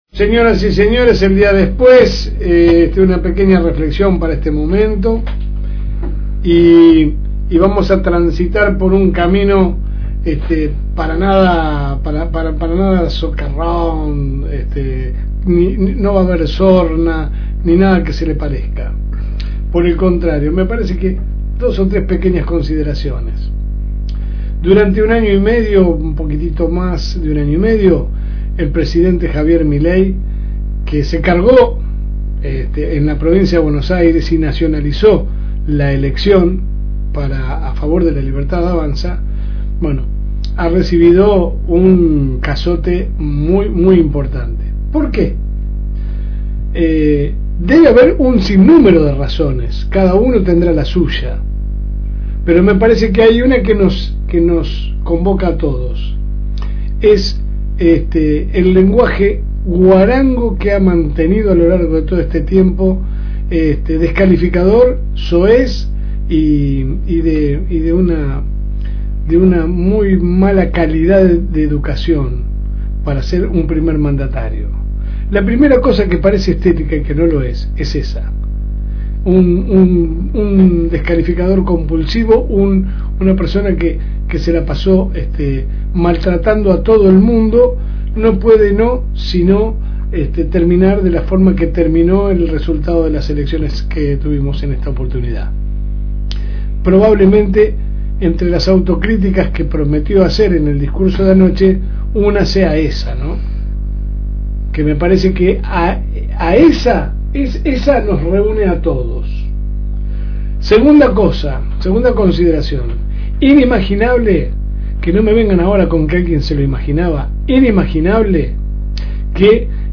La editorial a continuación.